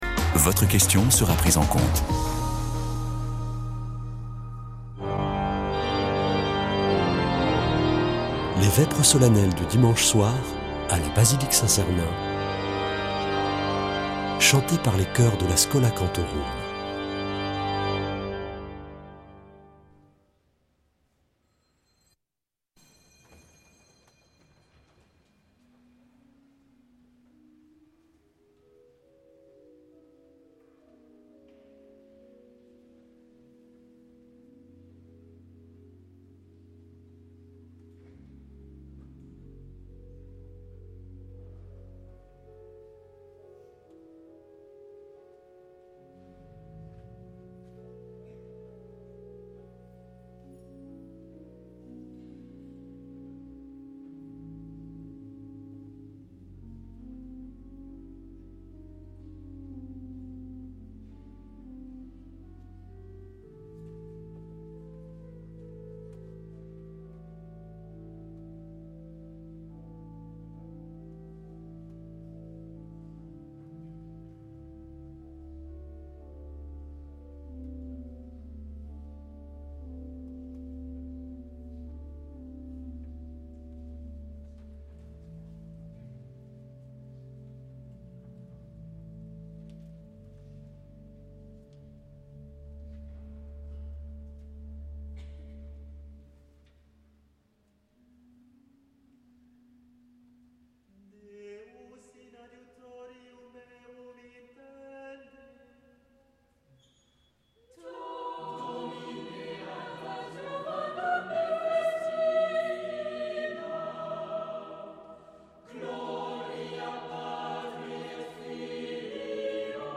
Chanteurs